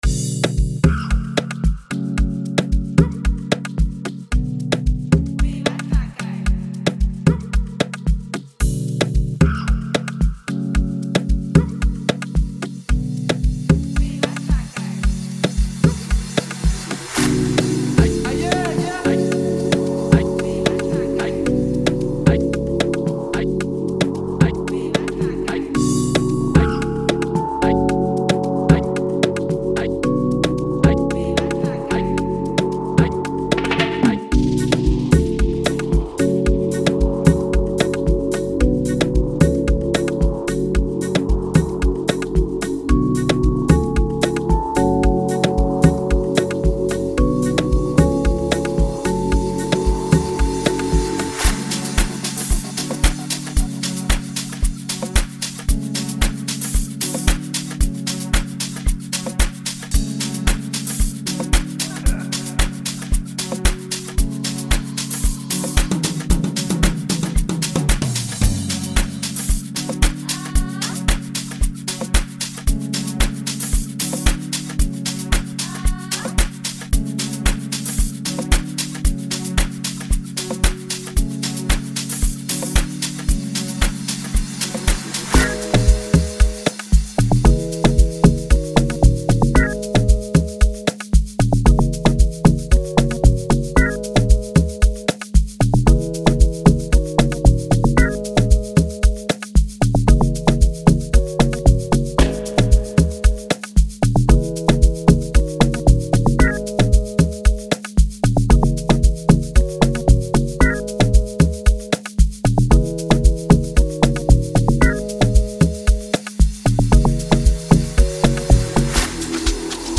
·     42 Melody Loops
·     21 Drum Loops
·     32 Percussions Loops
·     30 Shakers Loops